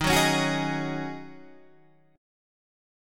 D# Minor 11th